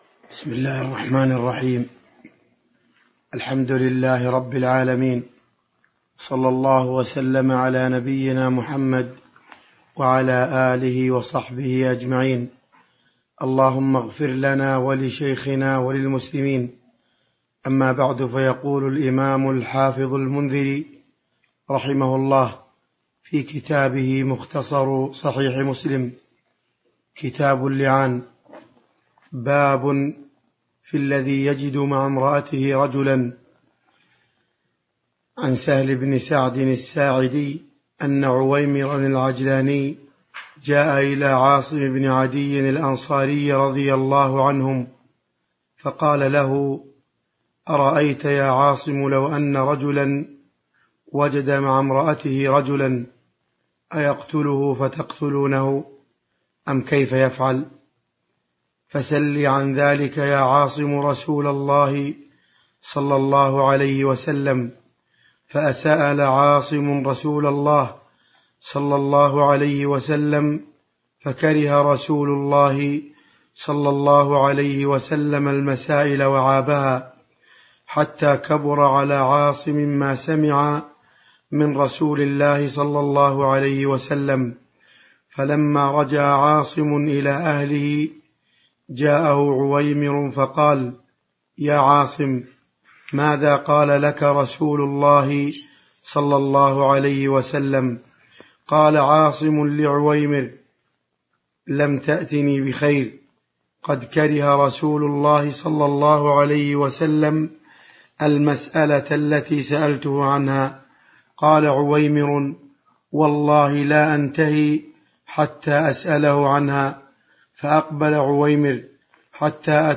تاريخ النشر ٢٠ محرم ١٤٤٣ هـ المكان: المسجد النبوي الشيخ